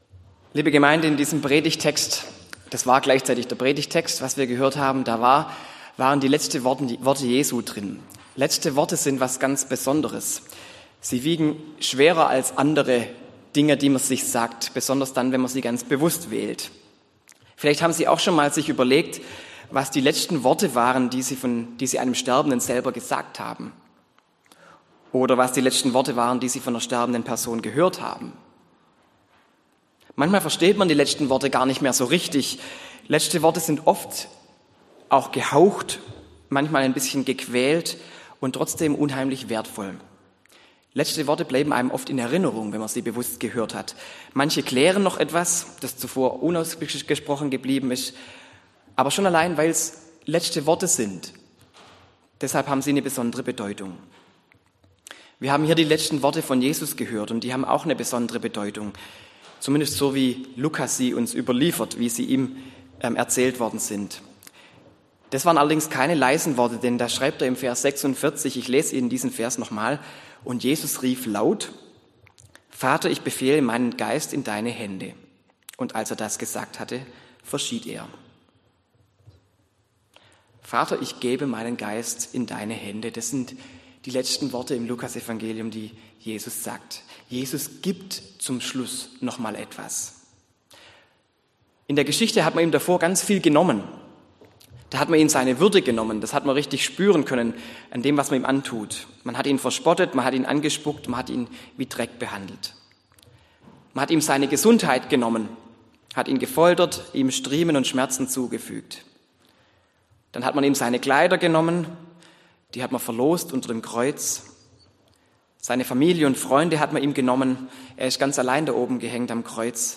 Fünfte Predigt zur Themenreihe "Hingabe"